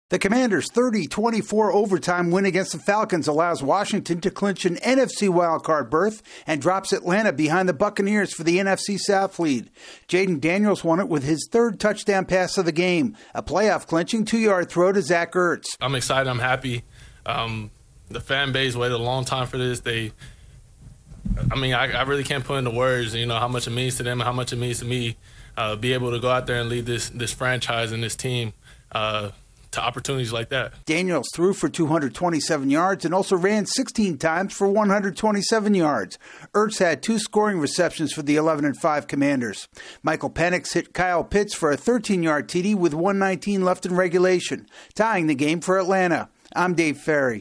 The Commanders are playoff bound for the first time since 2020. AP correspondent